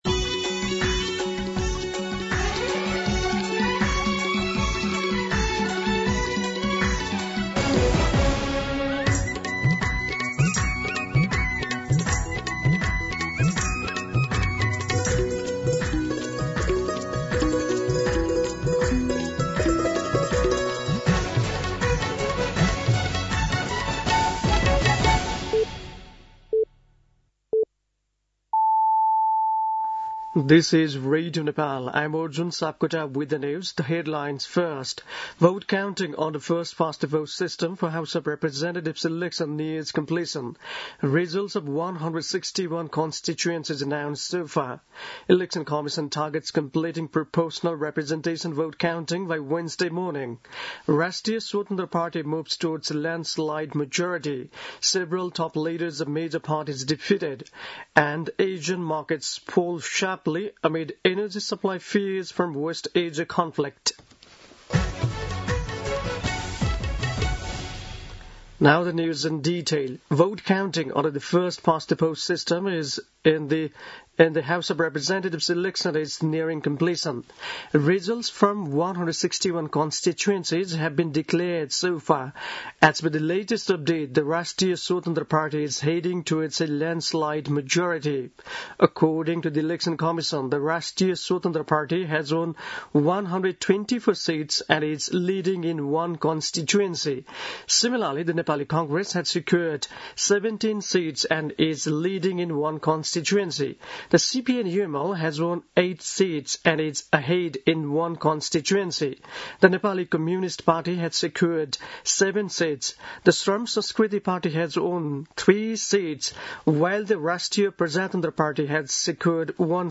दिउँसो २ बजेको अङ्ग्रेजी समाचार : २५ फागुन , २०८२
2-pm-English-News.mp3